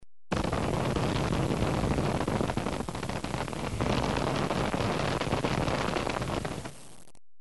جلوه های صوتی
دانلود صدای بمب و موشک 10 از ساعد نیوز با لینک مستقیم و کیفیت بالا